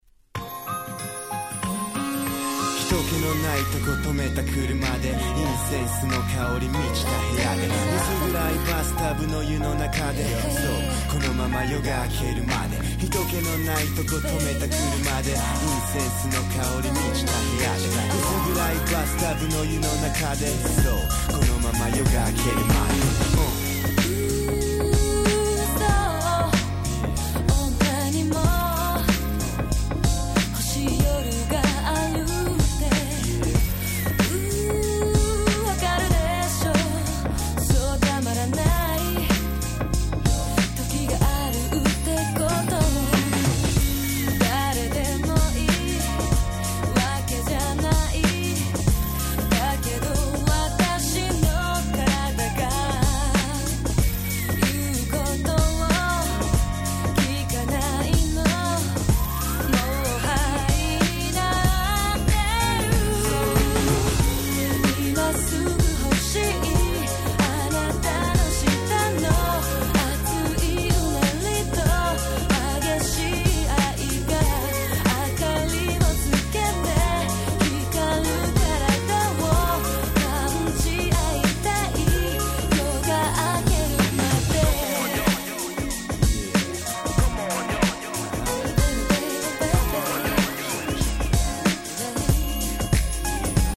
98' Nice Japanese R&B !!